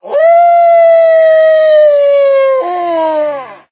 wolf
howl2.ogg